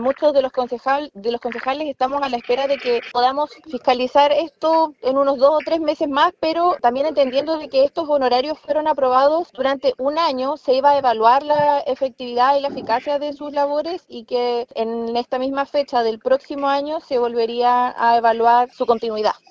concejala-barbara-alvarex.mp3